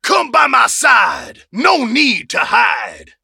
buster_ulti_vo_04.ogg